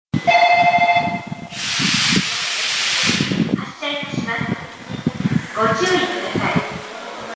駅放送
発車 小音。少し響く。 駅ホームには絶えず風が吹いています。